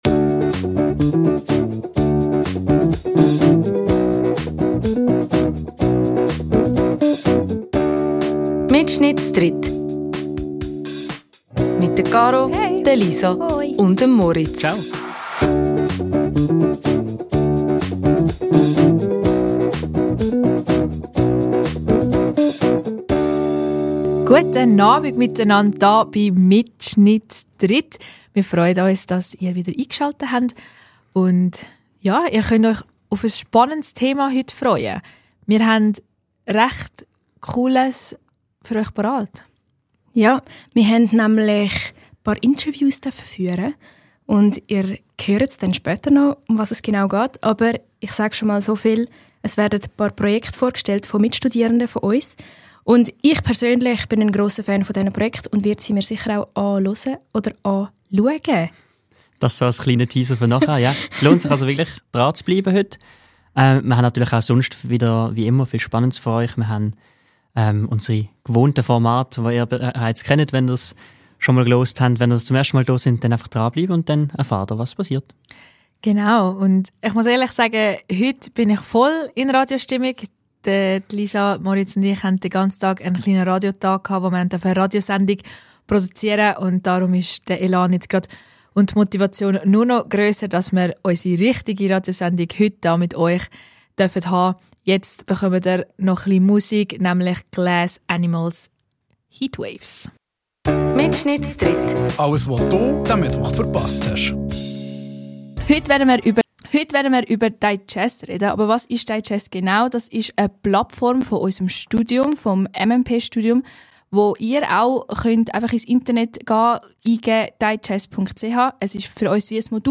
Die Wuche gids nöd nur eis, sondern grad zwei Interviews wo mer euch spannendi Projektarbeite vorstelled.